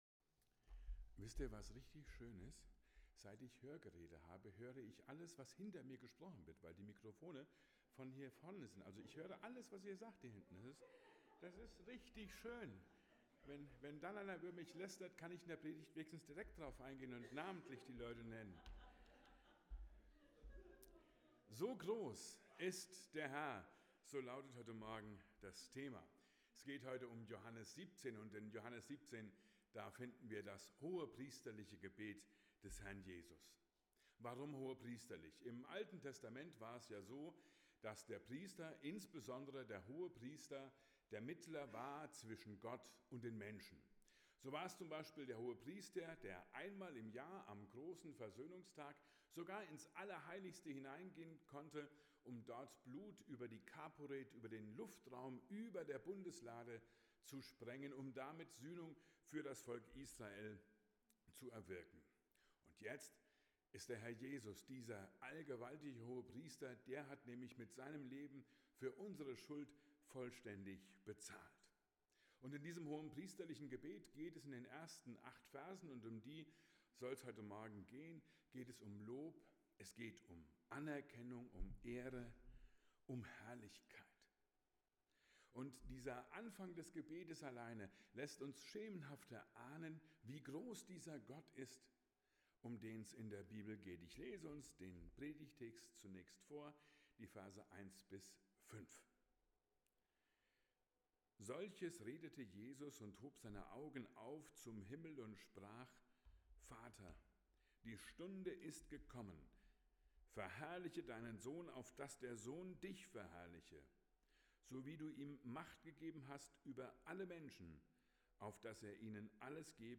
Predigt zum 3. Advent